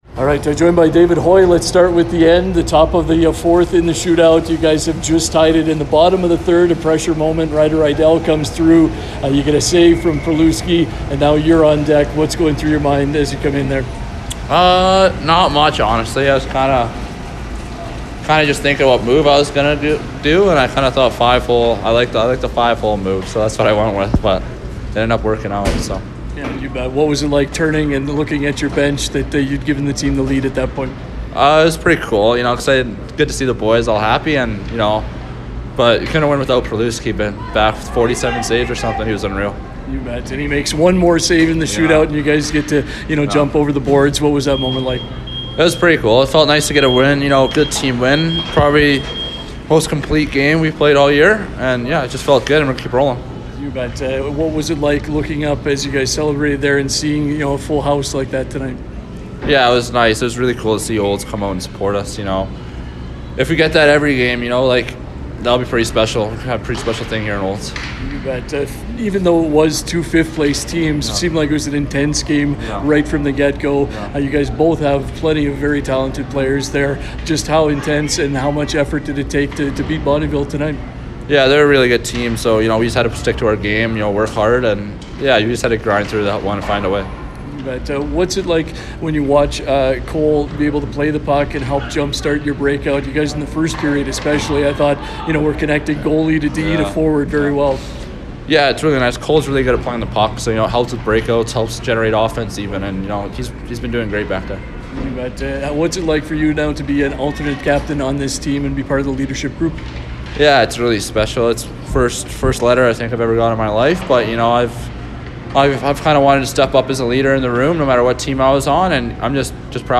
post-win conversation